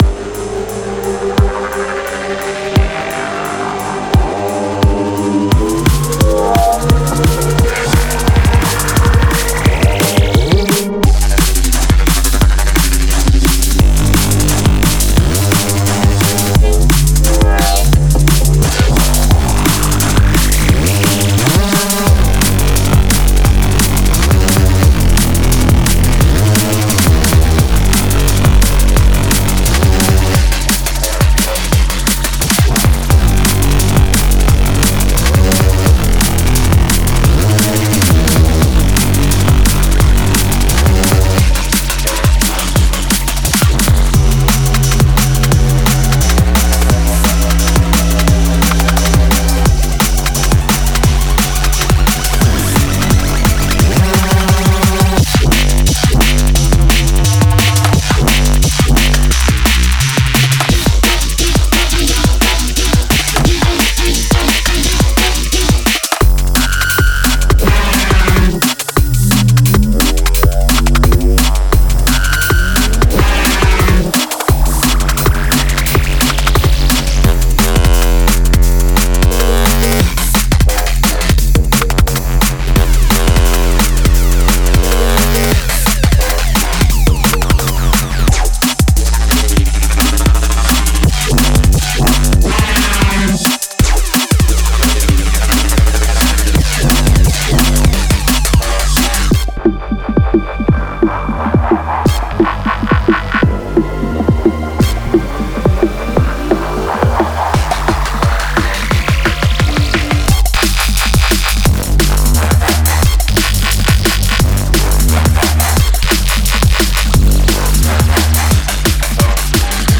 Genre:Neurofunk
デモサウンドはコチラ↓